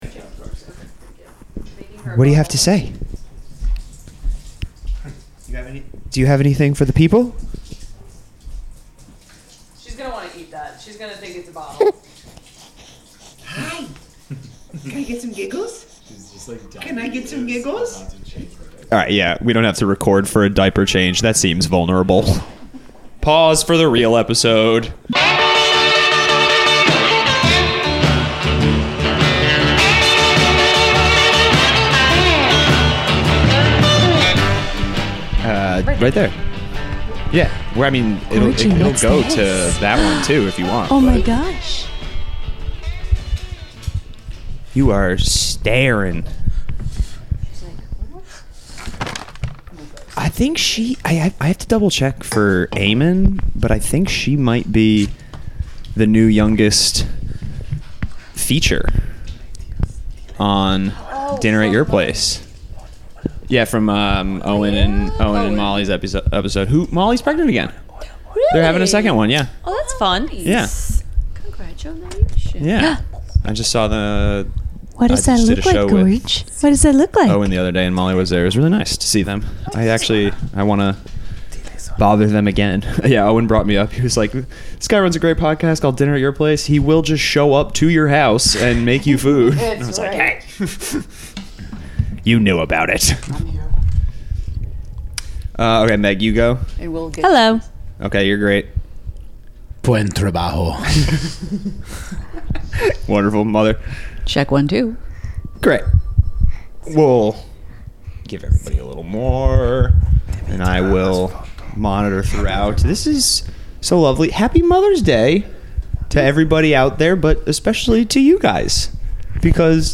The studio is back to being a studio and not my bedroom now that roommate mom has finished her vacation. Before she left, we did a new segment where followers sent in anonymous questions to be answered by someone who has a bit of life experience.